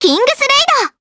Estelle-Vox_Kingsraid_jp.wav